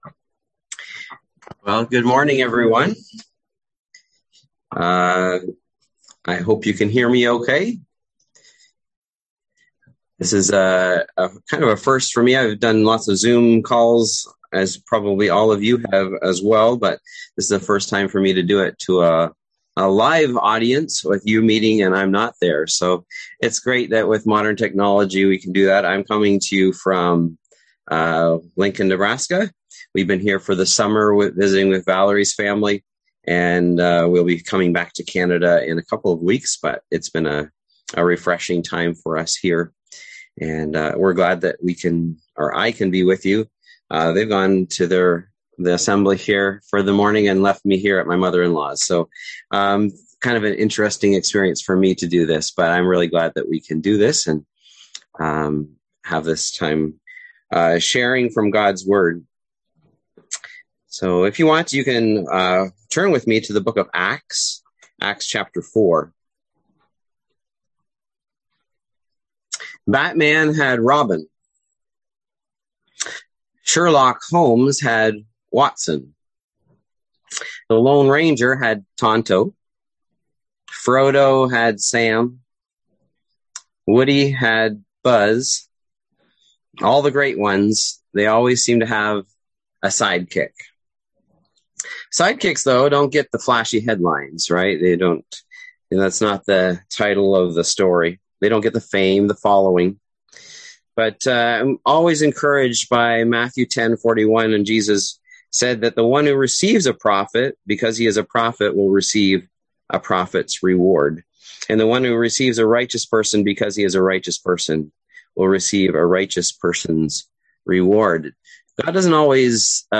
Service Type: Sunday AM Topics: Encouragement , Forgiveness , Generosity